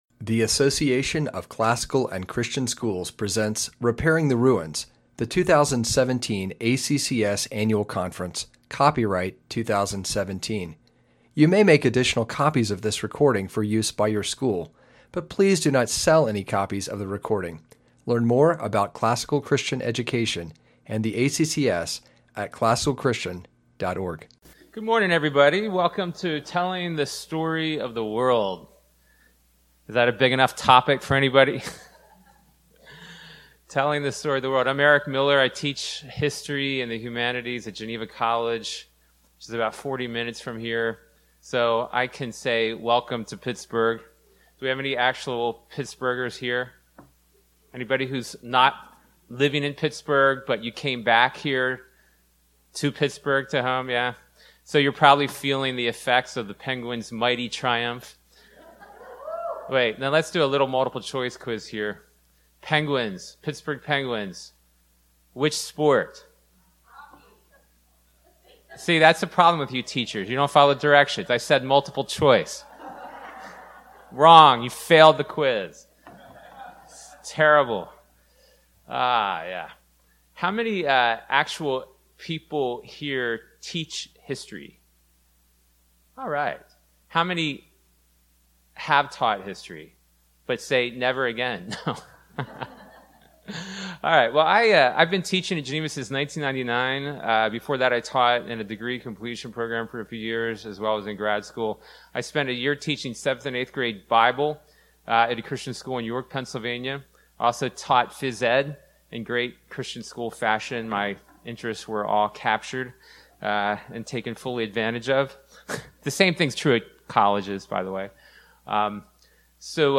2017 Foundations Talk | 1:00:21 | All Grade Levels, History
Speaker Additional Materials The Association of Classical & Christian Schools presents Repairing the Ruins, the ACCS annual conference, copyright ACCS.